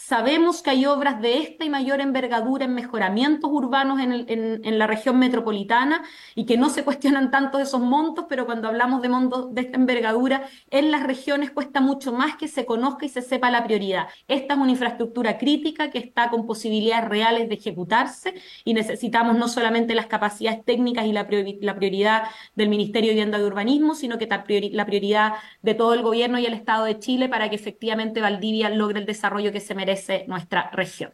Un llamado al que se sumó la alcaldesa de Valdivia, Carla Amtmann, que participó de la Comisión vía telemática, donde hizo una presentación de casi 10 minutos sobre la importancia del puente.